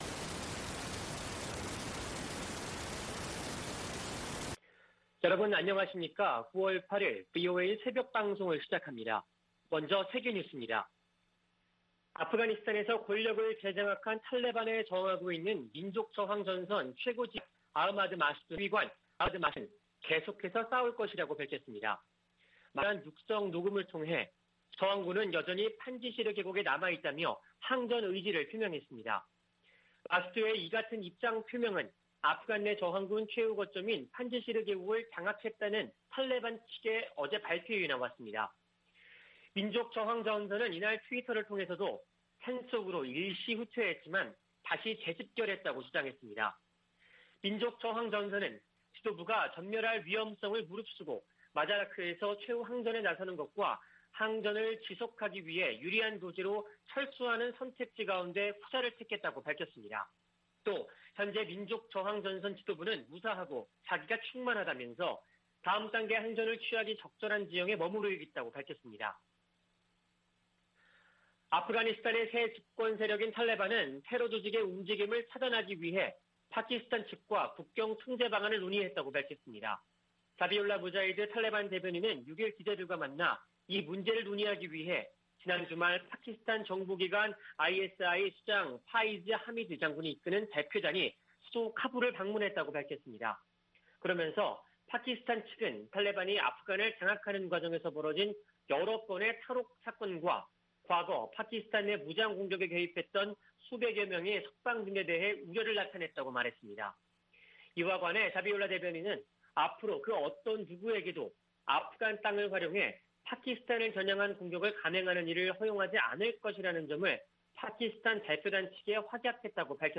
VOA 한국어 '출발 뉴스 쇼', 2021년 9월 8일 방송입니다. 북한이 핵무기와 미사일 관련 국제 규칙을 노골적으로 무시하고 있다고 NATOㆍ나토 사무총장이 밝혔습니다. 오는 14일 개막하는 제 76차 유엔총회에서도 북한 핵 문제가 주요 안건으로 다뤄질 전망입니다. 아프가니스탄을 장악한 탈레반이 미군 무기를 북한에 판매하지 않을 것이라고 밝혔습니다.